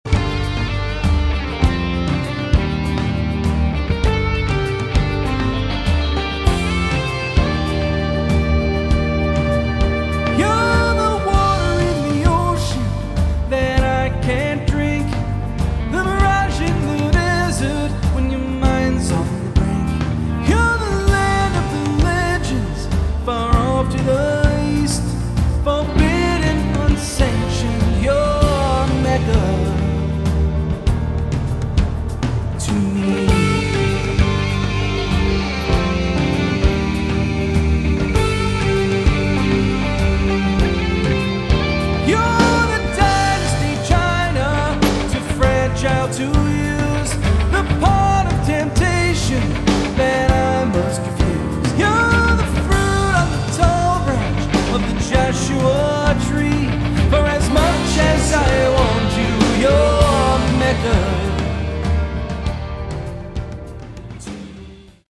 Category: AOR
lead vocals
guitars
bass guitar
drums & percussion
keyboards